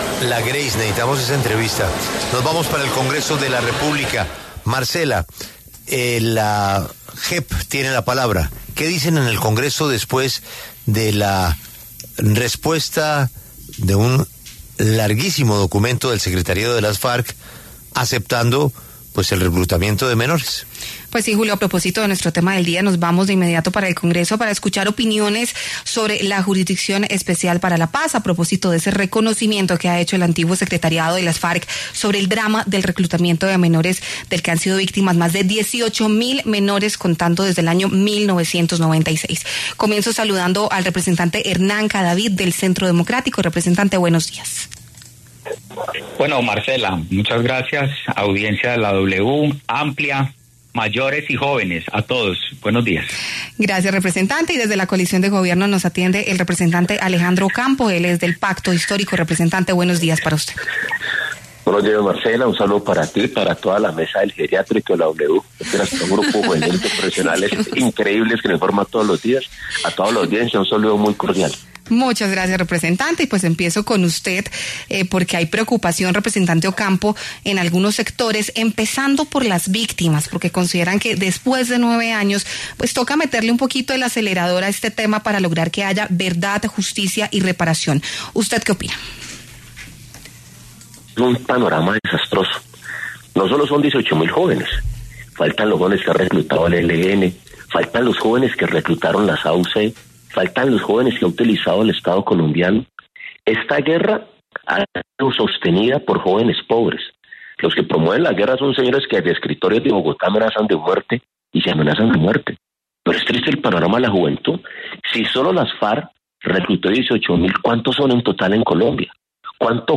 Los representantes Hernán Cadavid (Centro Democrático) y Alejandro Ocampo (Pacto Histórico) debatieron en La W sobre el reciente reconocimiento del exsecretariado de las FARC ante la JEP por el reclutamiento de más de 18.000 menores.